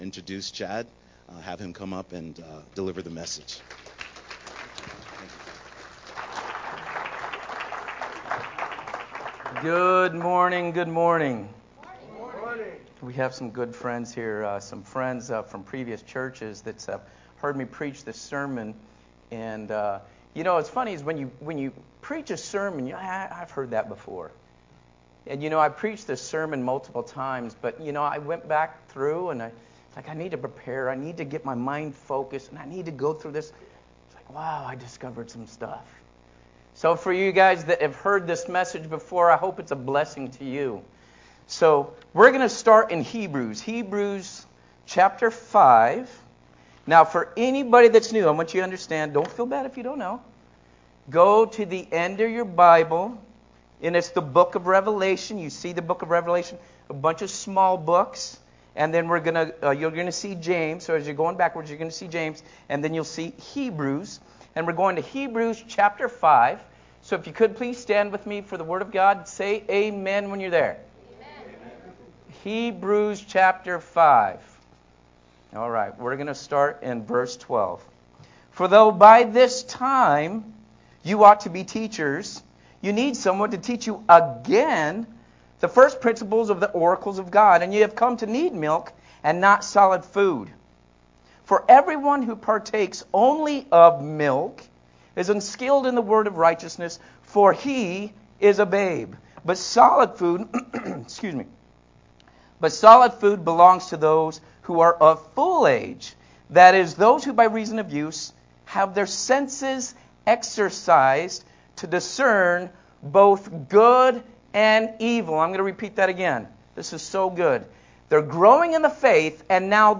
Sermon From Hebrews 5:12-14